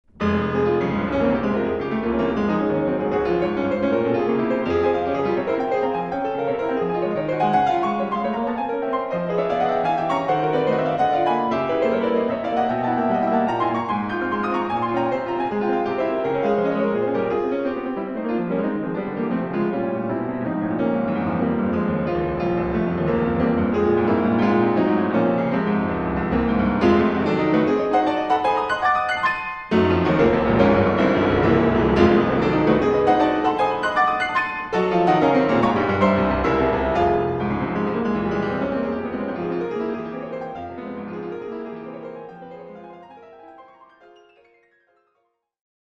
“outstanding ... dramatic”
Melba Hall
Classical, Keyboard